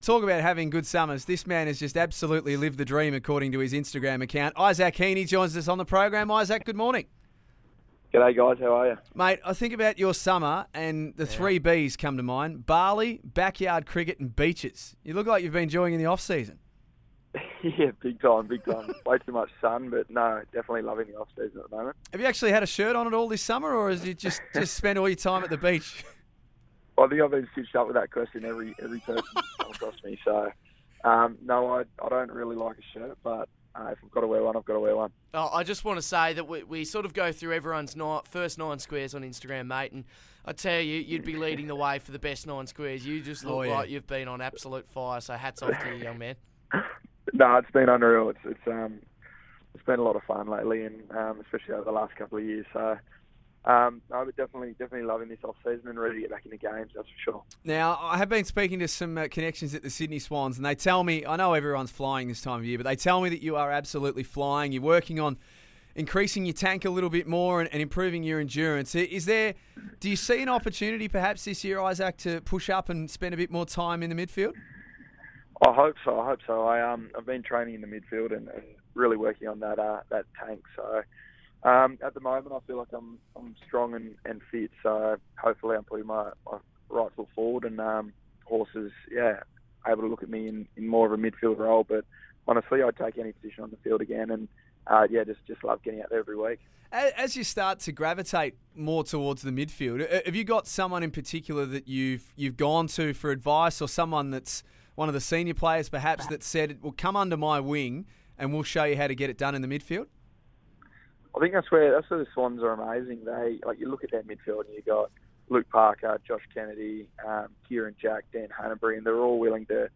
Isaac Heeney speaks to the crew from EON Sports Radio.